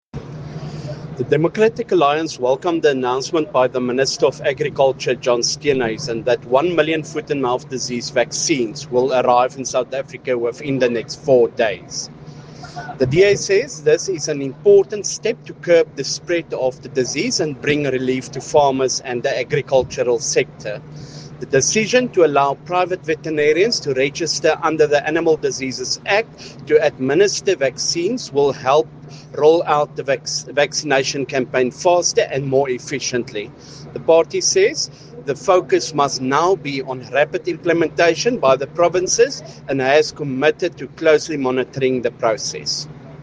Issued by Beyers Smit MP – DA Spokesperson on Agriculture
Afrikaans audio clips by Beyers Smit MP.